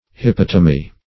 Search Result for " hippotomy" : The Collaborative International Dictionary of English v.0.48: Hippotomy \Hip*pot"o*my\, n. [Gr.